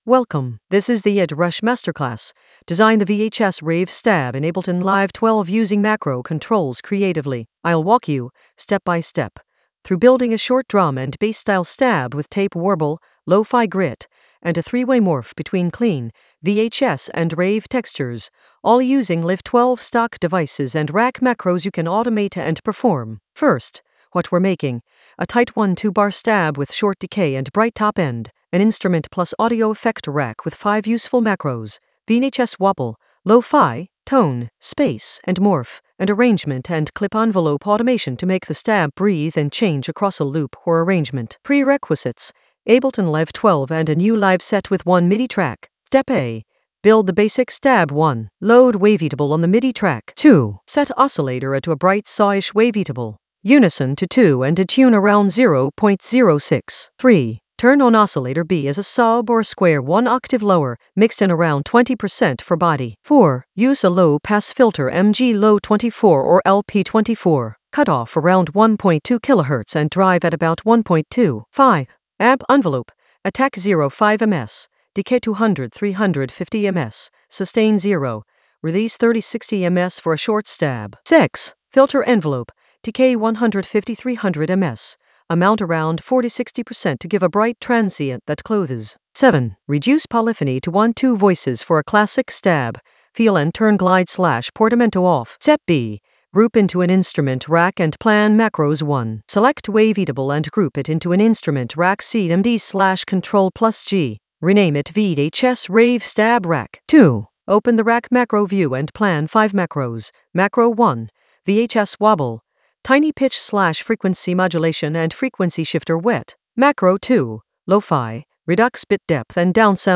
An AI-generated beginner Ableton lesson focused on Ed Rush masterclass: design the VHS-rave stab in Ableton Live 12 using macro controls creatively in the Automation area of drum and bass production.
Narrated lesson audio
The voice track includes the tutorial plus extra teacher commentary.